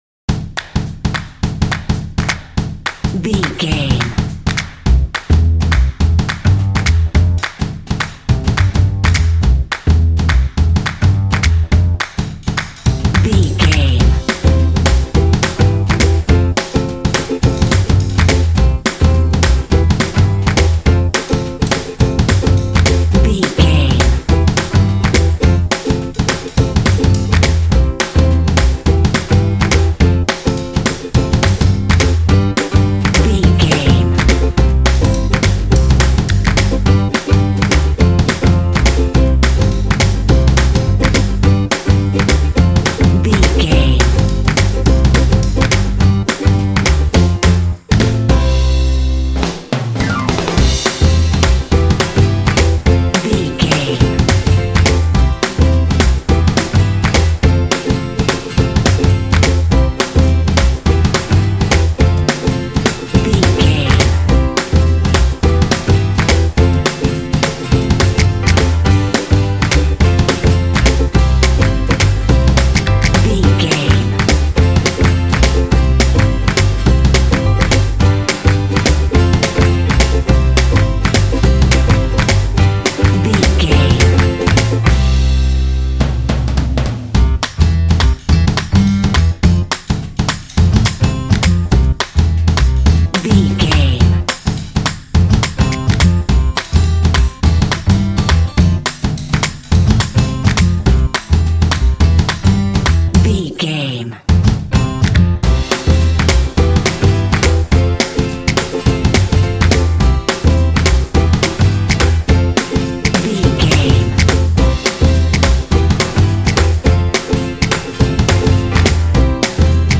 Uplifting
Ionian/Major
bouncy
energetic
groovy
drums
percussion
double bass
acoustic guitar
piano
pop
contemporary underscore